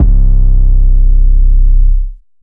TM88 DistKick808.wav